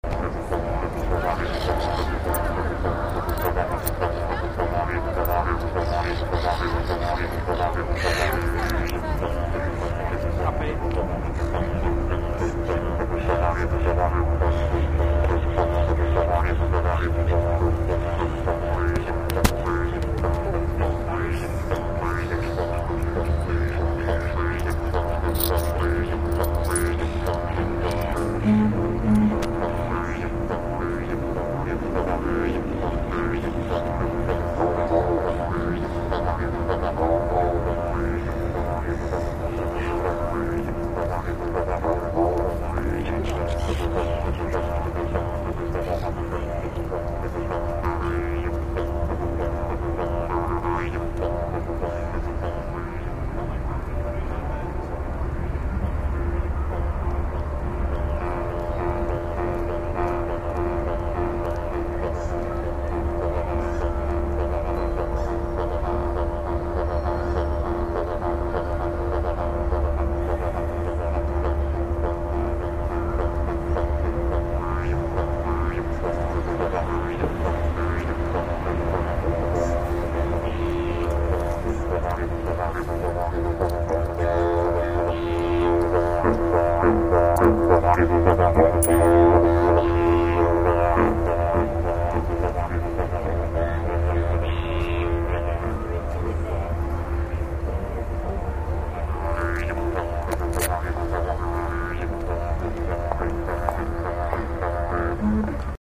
I have several hours of nice field recordings on mini-disc, some of which will eventually make it up here as mp3s, but this will happen gradually.
• An Aboriginal playing digeridoo at Circular Quay, Sydney, September 22.
digeridoo-circular_quay.mp3